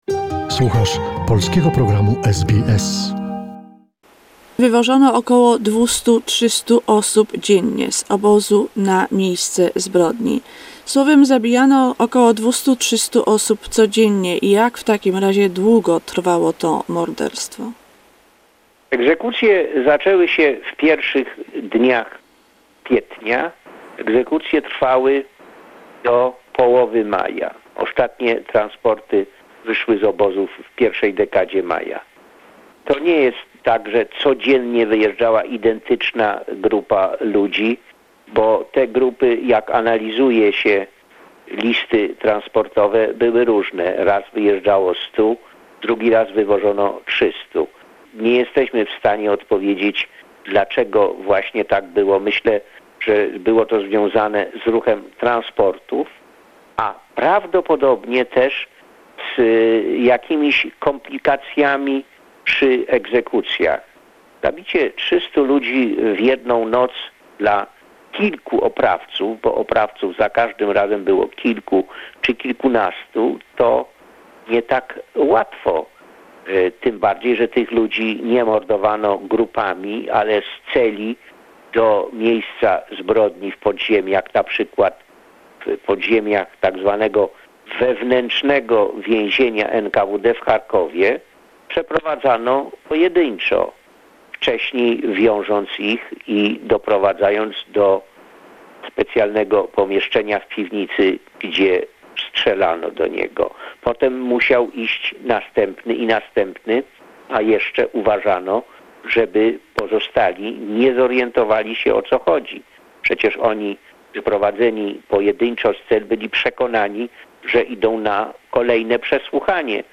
An archive conversation with a historian